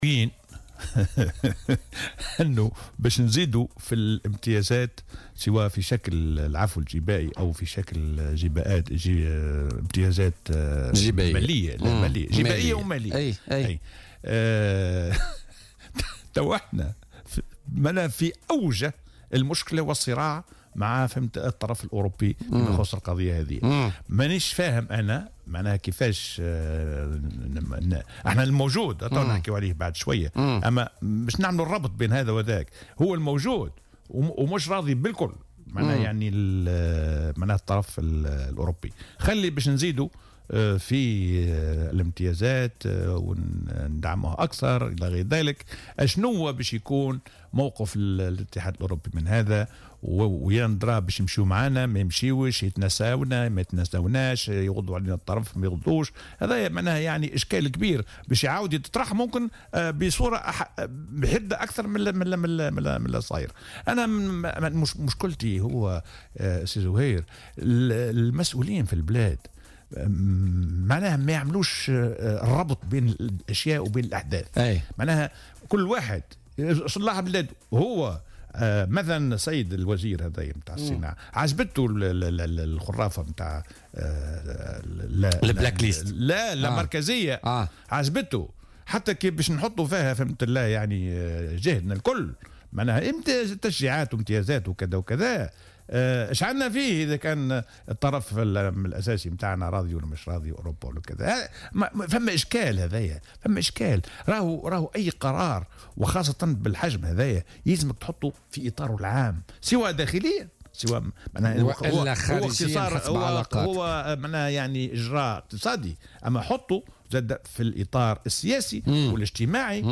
وأضاف في مداخلة له اليوم في برنامج "بوليتيكا" أن أي قرار بهذا الحجم يجب أن يوضع في إطاره العام مع الأخذ بعين الاعتبار المناخ الخارجي، وفق تعبيره.